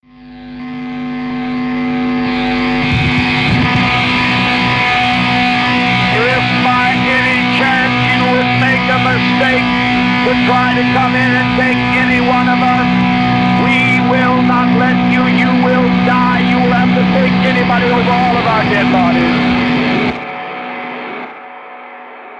Ének